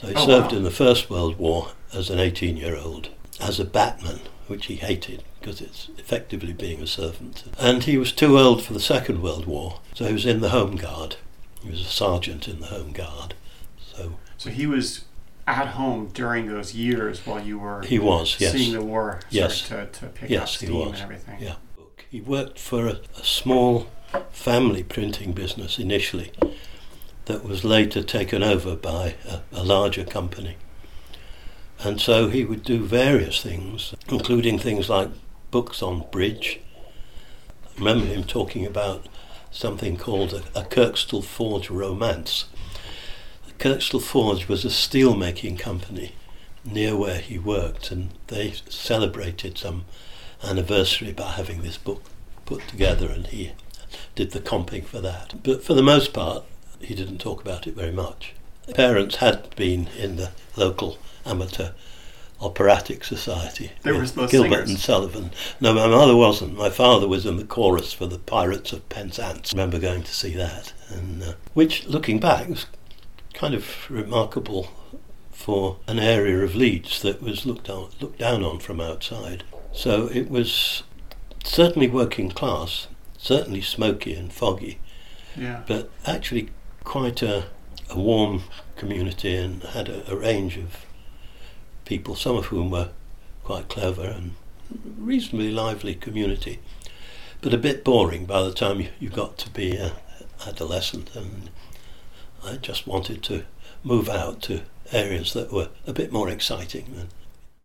I am thrilled to share with you some excerpts and ideas from my lively conversation with the one of Psychology’s bad asses, Alan Baddeley!
Dr. Baddeley introduces himself: